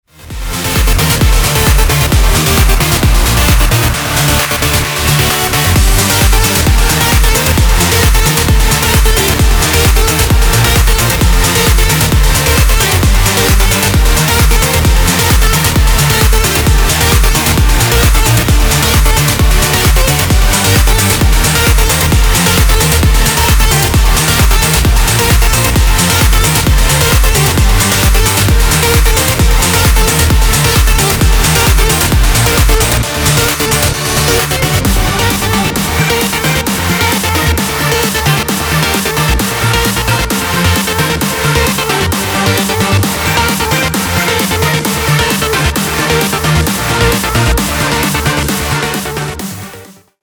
• Качество: 320, Stereo
Trance